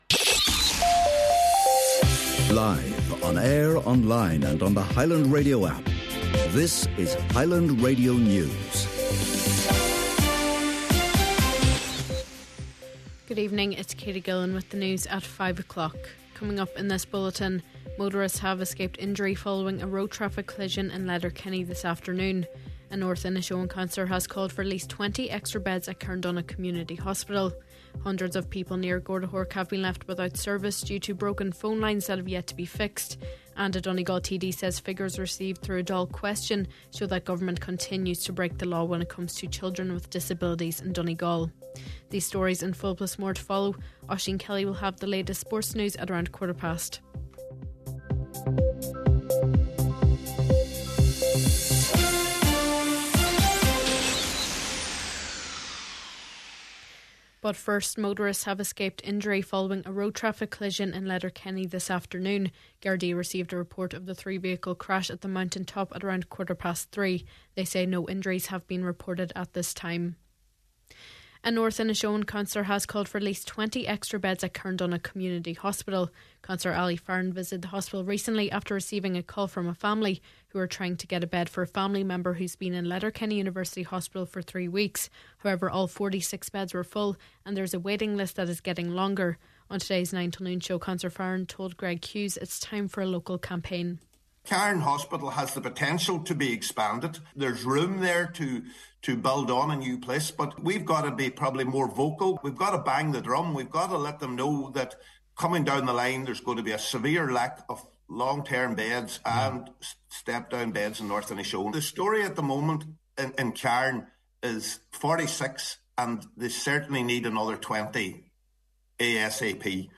Main Evening News, Sport and Obituary Notices – Tuesday November 25th